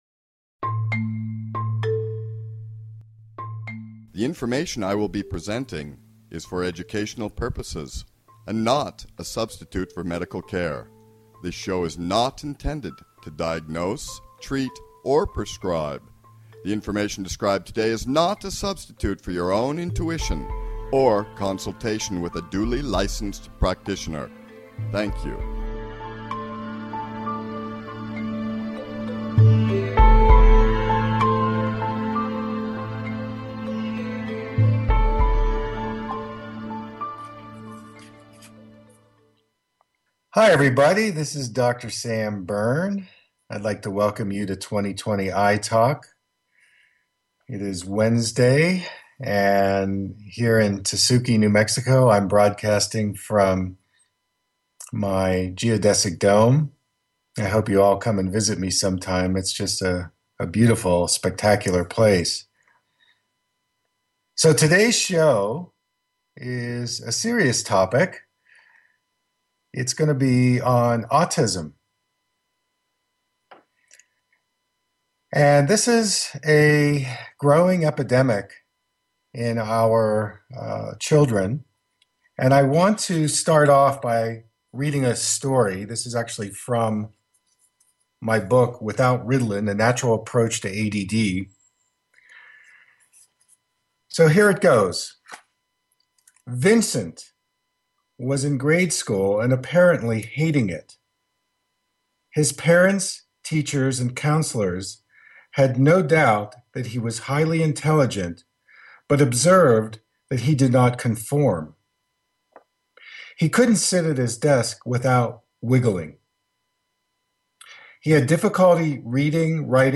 Talk Show Episode
Call in to receive guidance on how to improve your wellness and listen to stimulating interviews with leaders in the holistic health field. Dynamic, engaging, and funny, this is the show you have been looking for!